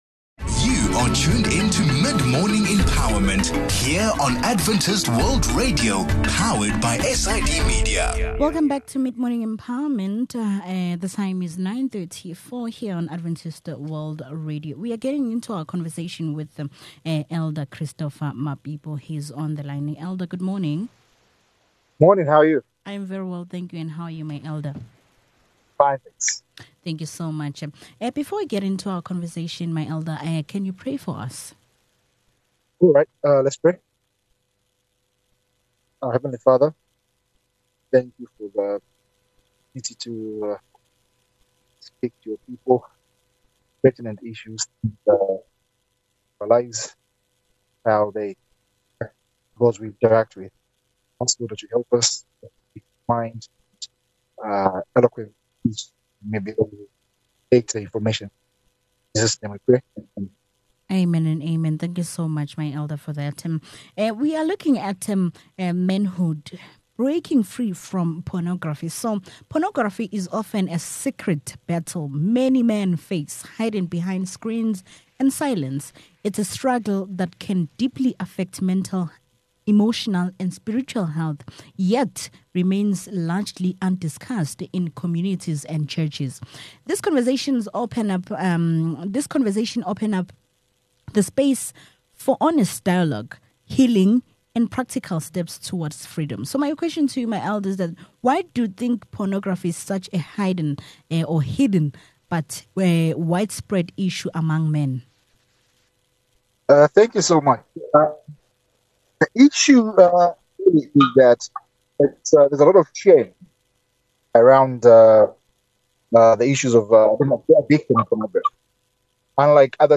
This conversation opens up the space for honest dialogue, healing, and practical steps toward freedom.